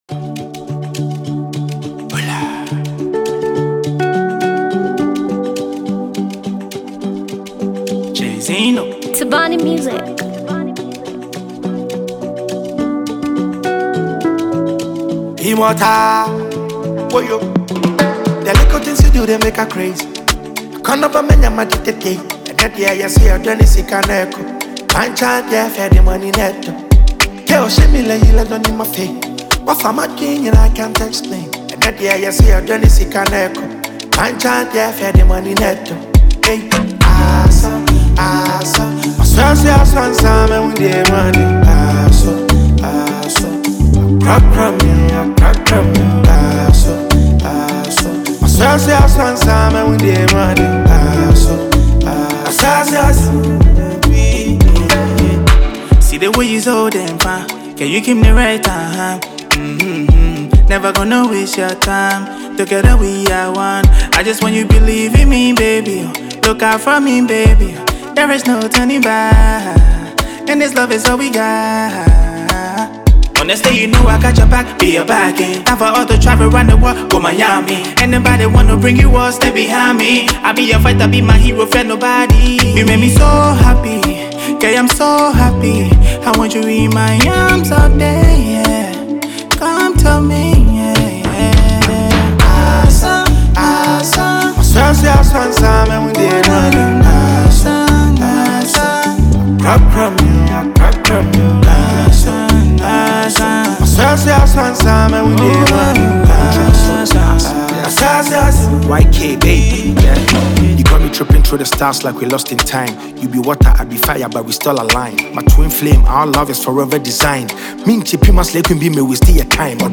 a powerful blend of rap and Afro vibes